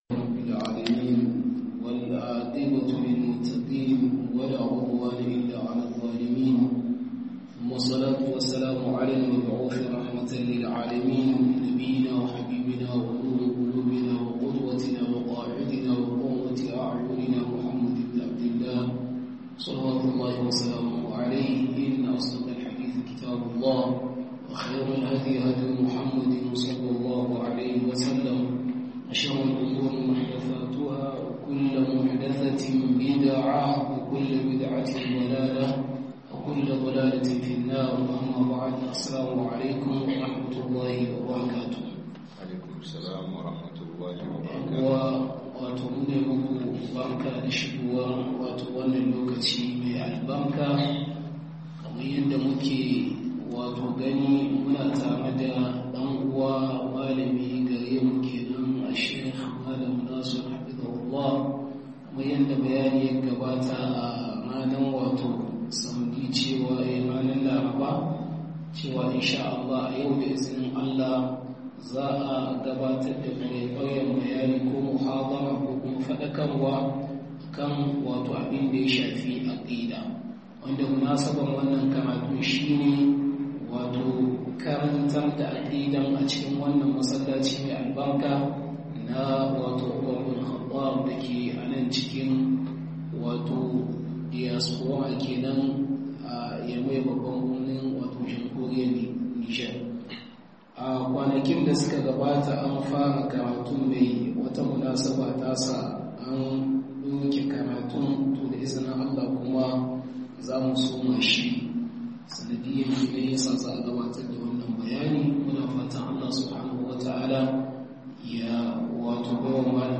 Aqida da muhimmancin ta - Muhadara